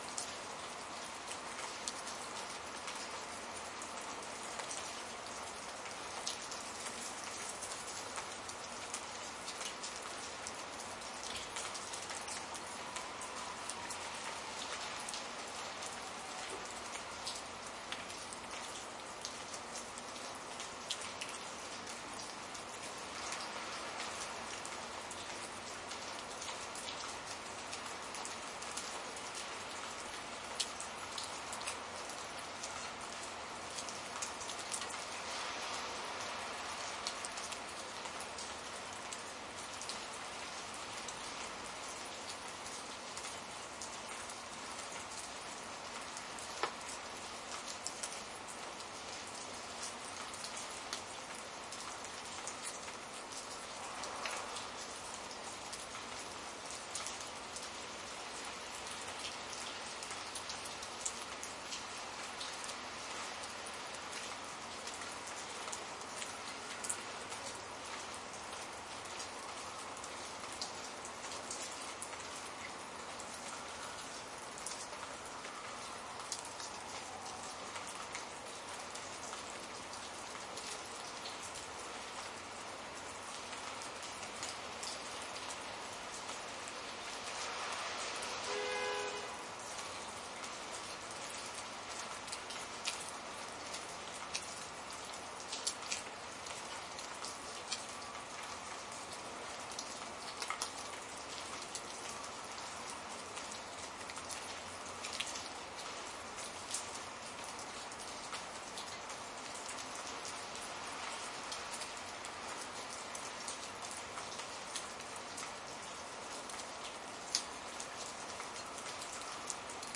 蒙特利尔 " 阳台 冬季湿润融化的雪滴+遥远的汽车穿过泥泞的水坑 蒙特利尔，加拿大
描述：阳台冬天湿融化的雪滴+遥远的汽车经过融雪水坑蒙特利尔，Canada.flac
Tag: 阳台 蒙特利尔 湿 冬天融化 加拿大